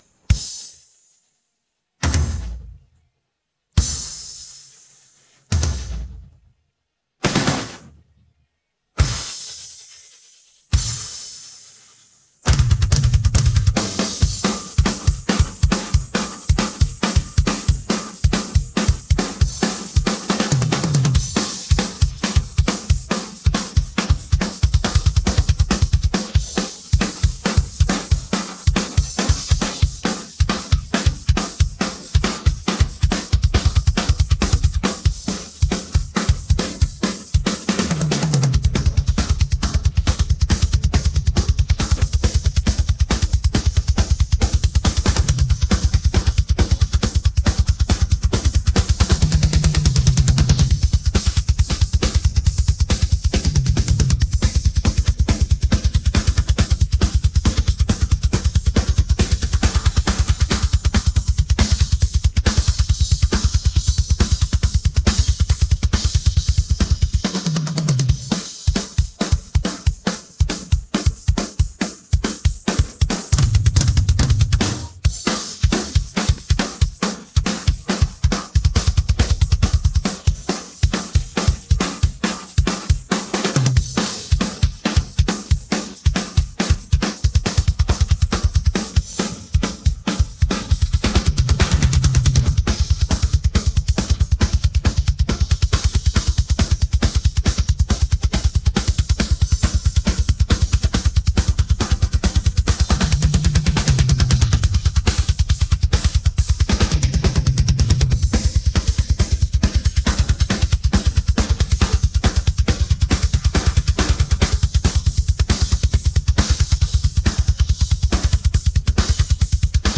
This is an isolation of the drums